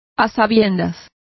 Complete with pronunciation of the translation of knowingly.